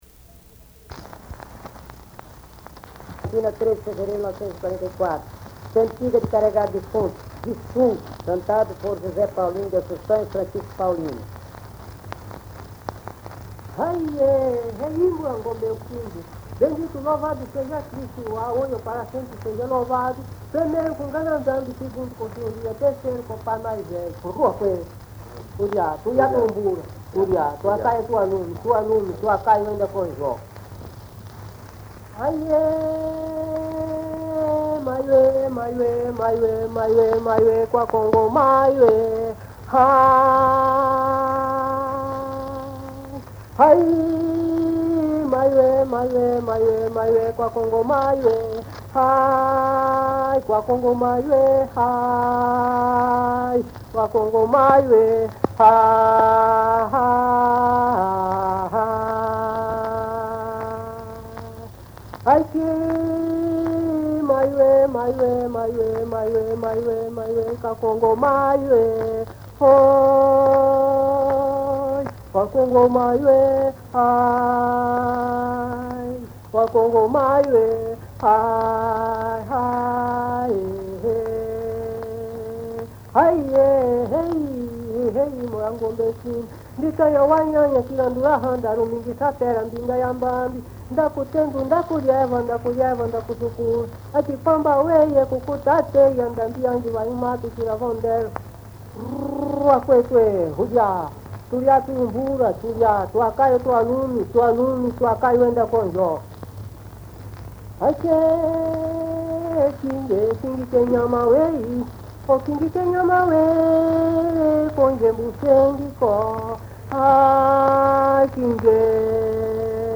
“Cantiga de levar defunto na rede” – Gravação de Vissungo da região de Diamantina, MG Fonograma 57a – Coleção Luiz Heitor Corrêa de Azevedo